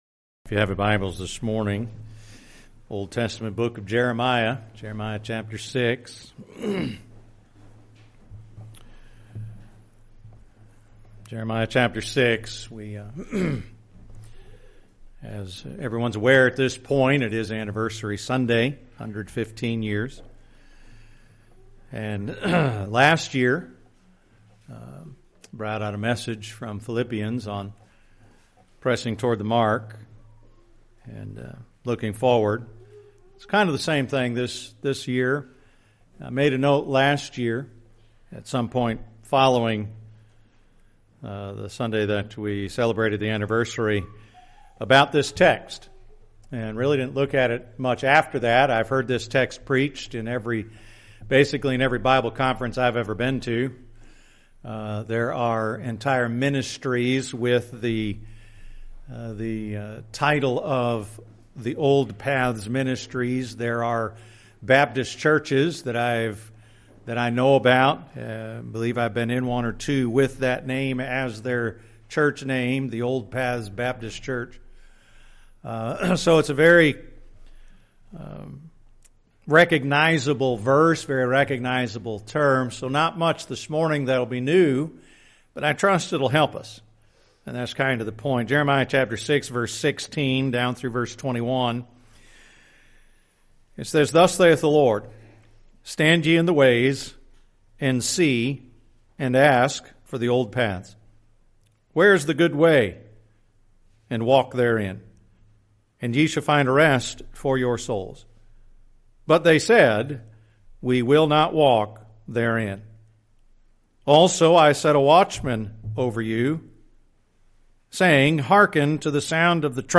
10/19/2025 – Sunday morning (Anniversary Sunday)